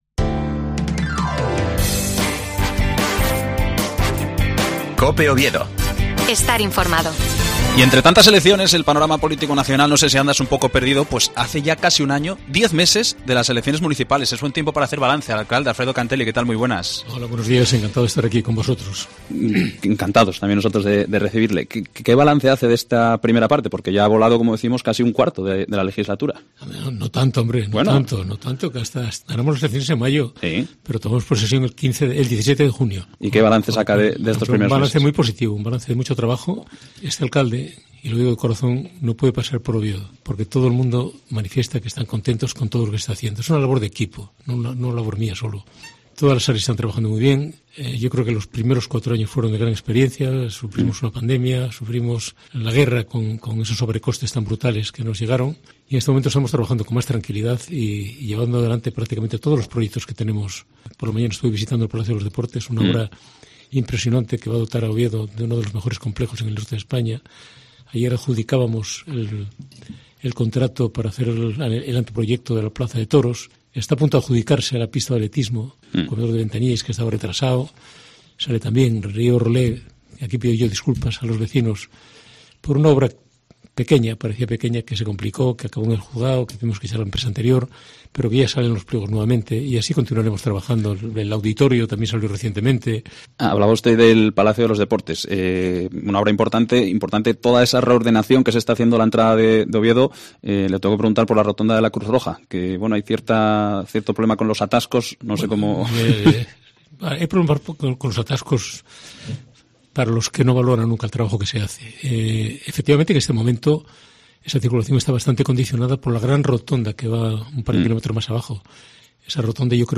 Entrevista a Alfredo Canteli (15/03/2024)